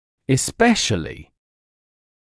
[ɪs’peʃəlɪ][ис’рэшэли]особенно, в особенности